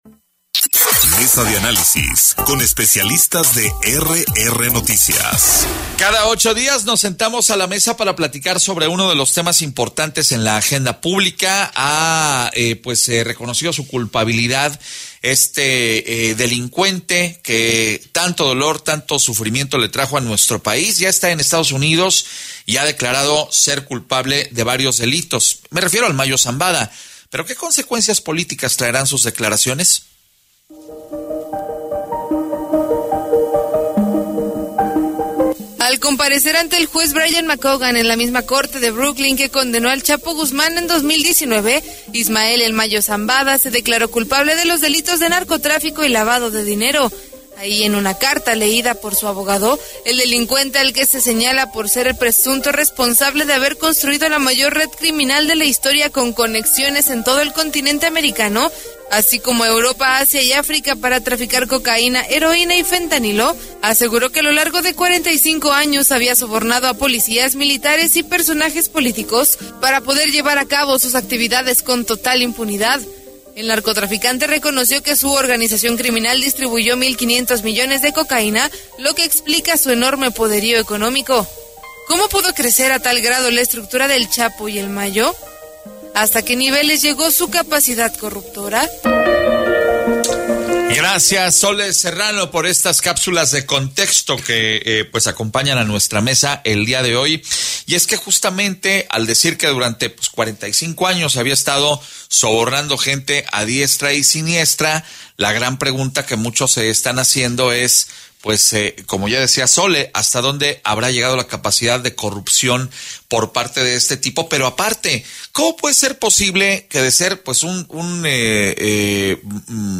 Mesa de Análisis Político Podcast Mesa: ¿Desatará «El Mayo» una tormenta política?
MESA-DE-ANALISIS-POLITICO.mp3